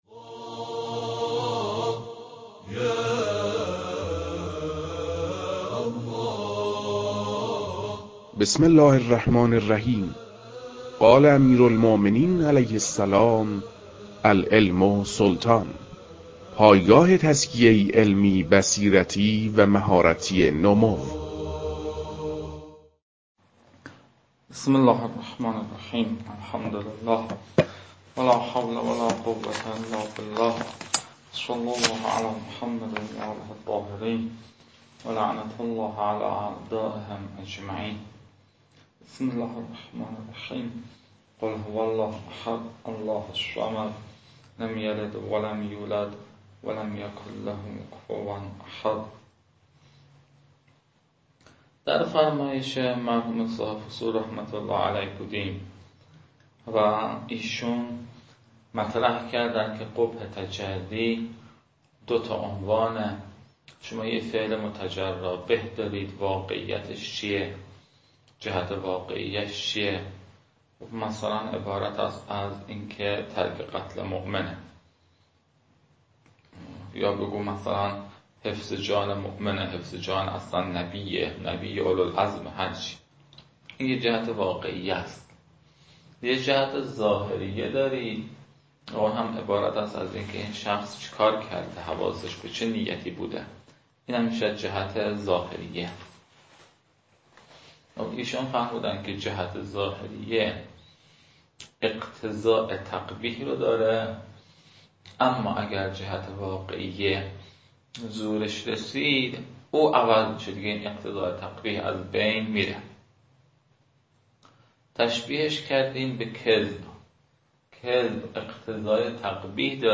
فایل های مربوط به تدریس مبحث رسالة في القطع از كتاب فرائد الاصول متعلق به شیخ اعظم انصاری رحمه الله